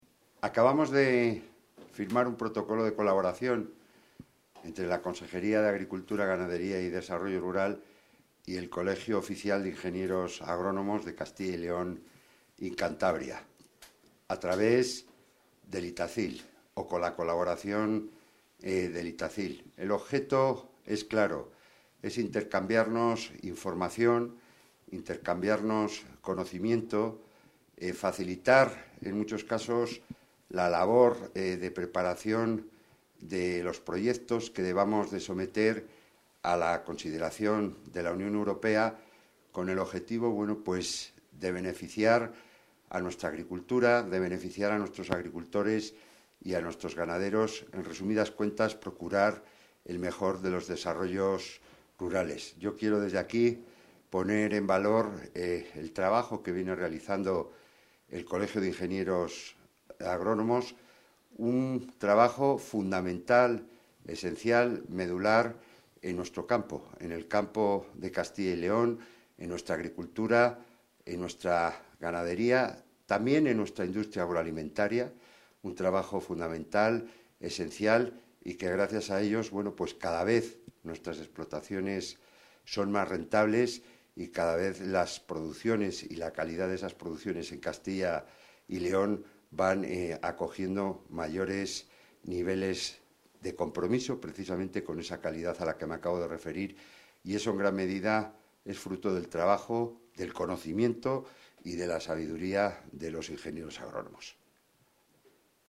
Declaraciones del consejero de Agricultura y Ganadería.